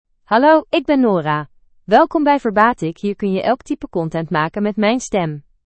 Nora — Female Dutch AI voice
Nora is a female AI voice for Dutch (Netherlands).
Voice sample
Listen to Nora's female Dutch voice.
Female
Nora delivers clear pronunciation with authentic Netherlands Dutch intonation, making your content sound professionally produced.